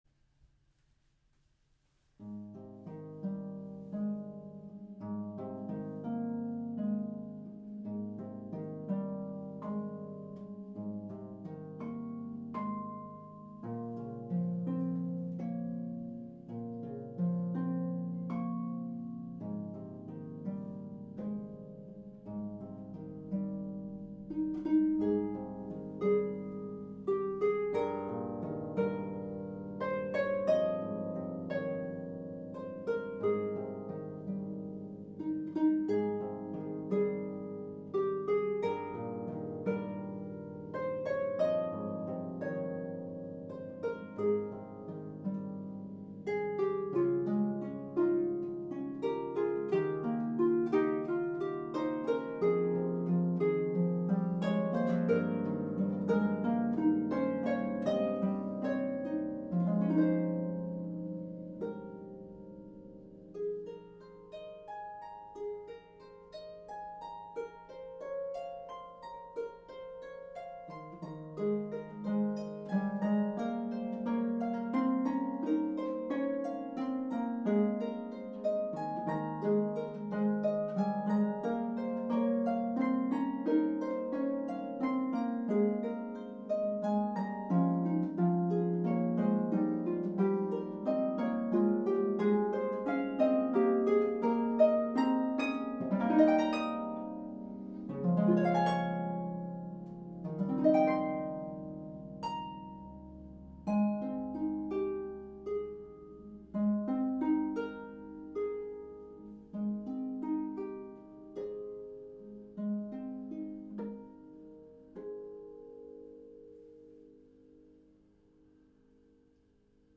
traditional Polish carol